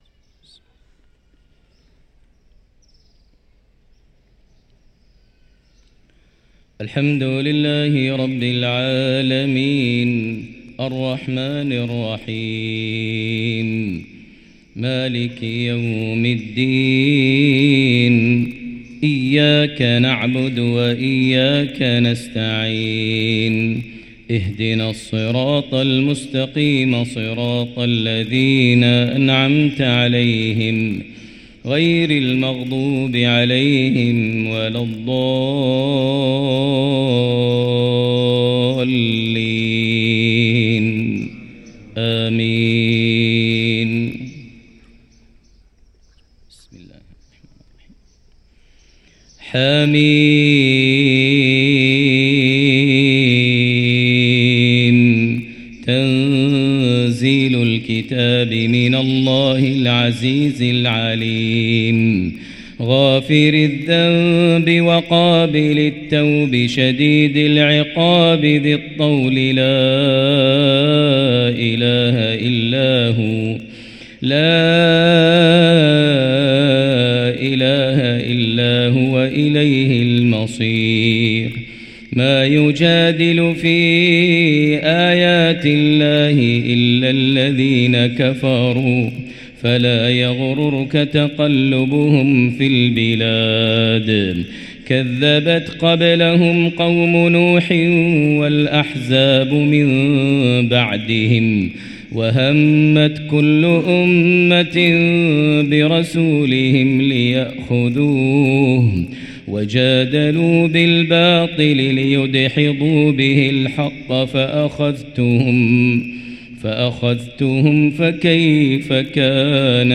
صلاة الفجر للقارئ ماهر المعيقلي 5 رجب 1445 هـ